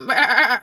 Animal_Impersonations
sheep_2_baa_07.wav